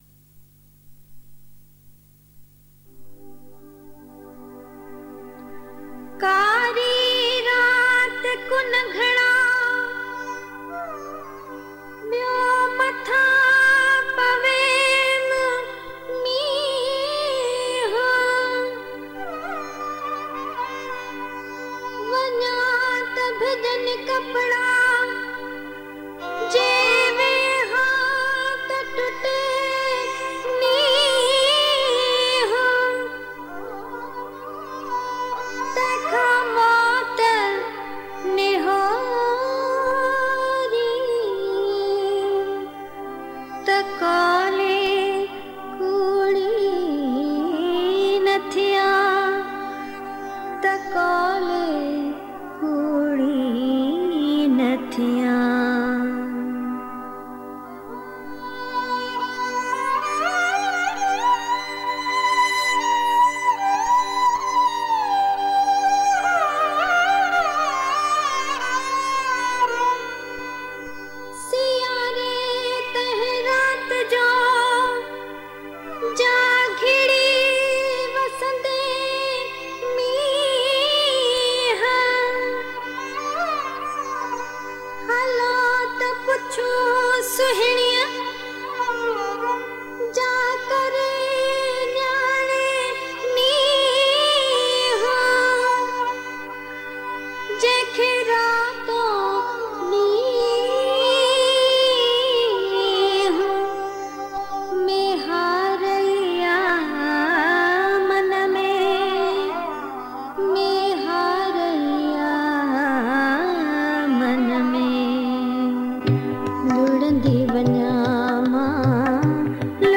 Sindhi Geet and Kalam